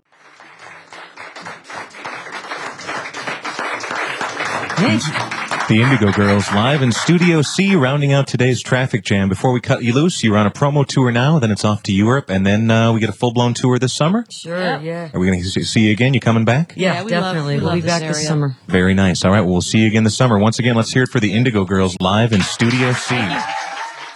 08. interview (0:24)